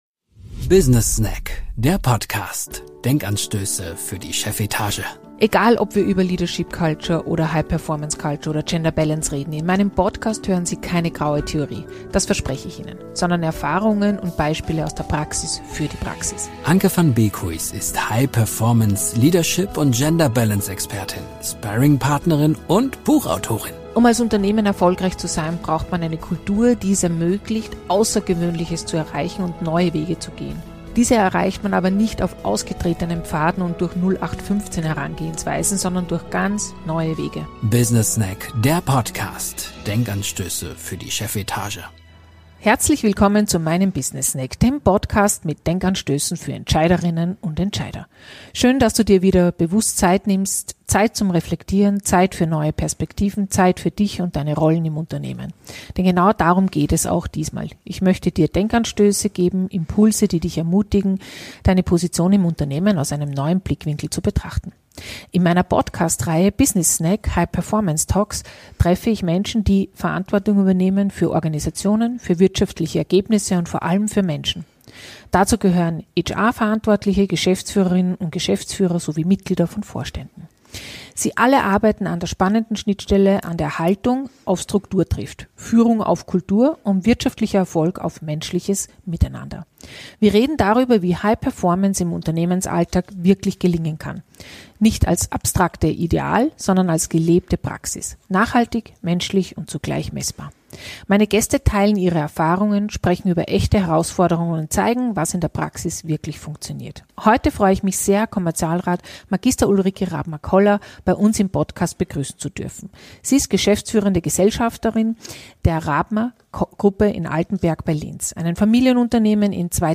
High Performance Talk